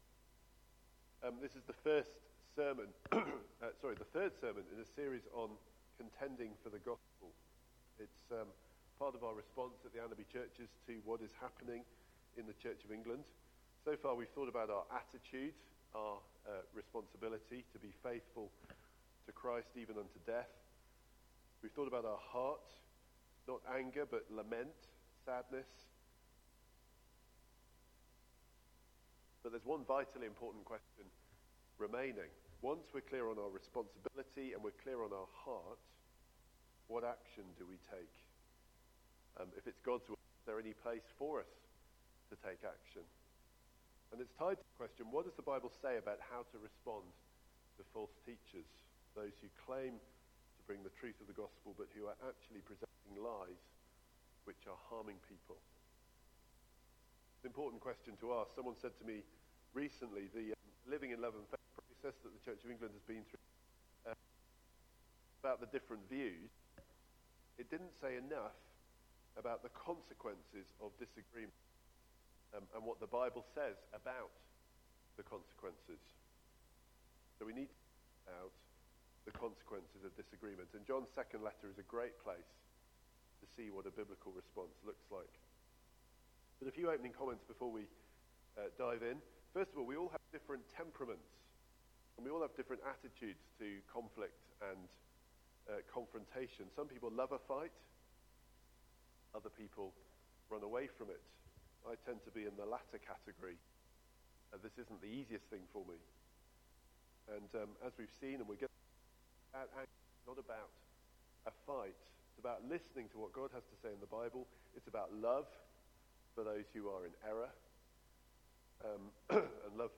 Media Library The Sunday Sermons are generally recorded each week at St Mark's Community Church.
Theme: Contending for the glory of God Sermon